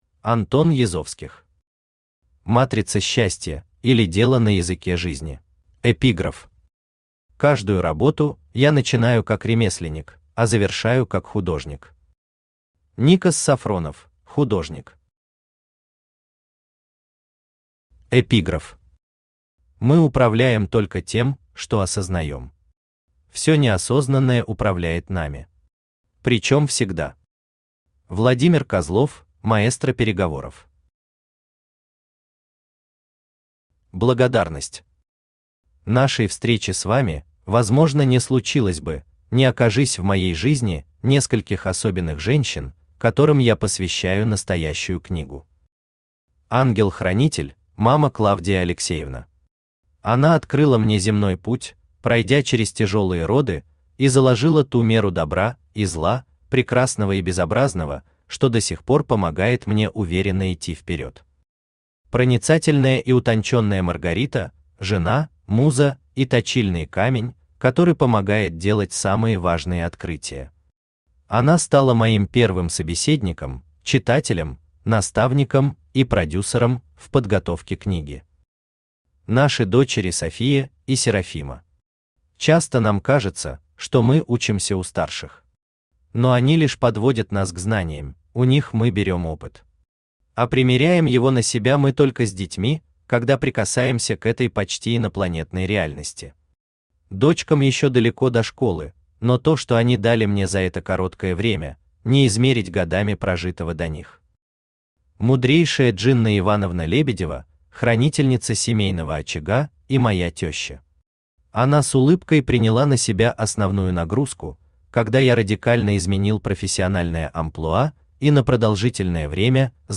Аудиокнига Матрица счастья, или Дело на языке Жизни | Библиотека аудиокниг